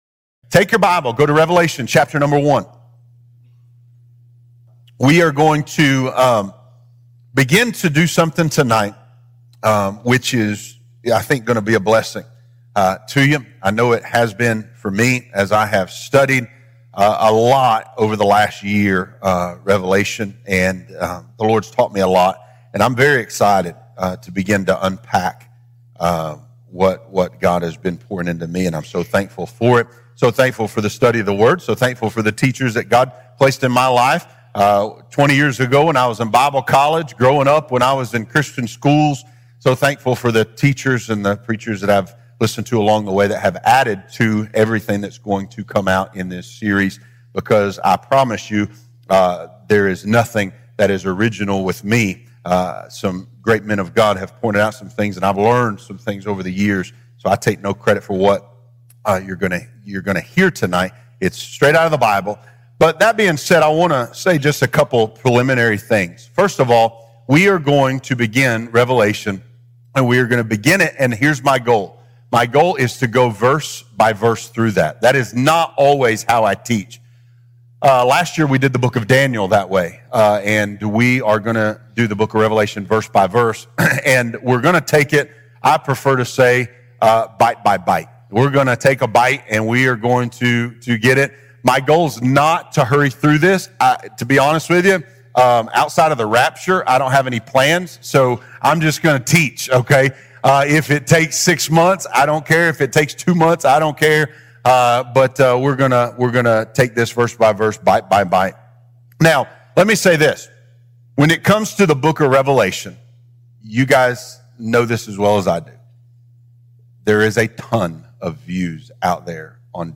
Have you ever wondered what the book of Revelation really means and how it relates to the current times? This is a verse-by-verse, bite-by-bite, in-depth Bible study that does not speculate or guess; letting Scripture speak and authenticate this important, but often overlooked book in the...